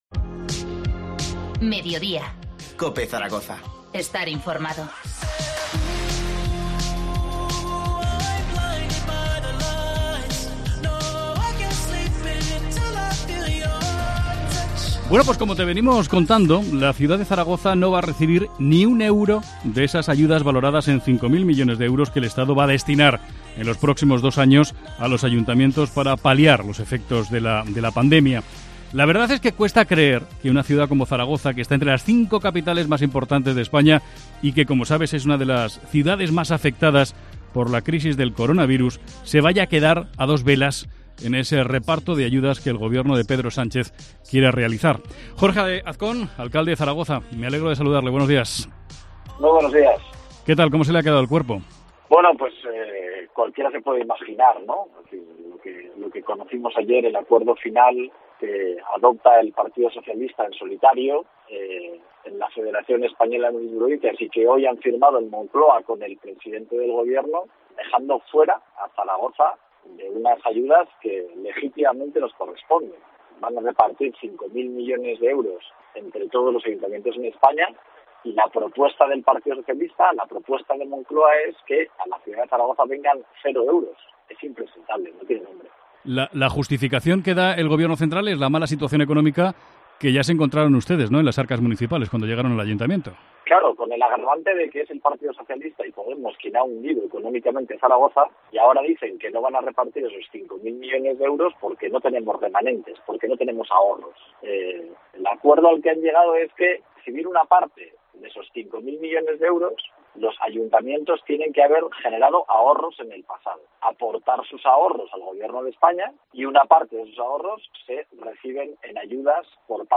Entrevista al alcalde de Zaragoza, Jorge Azcón.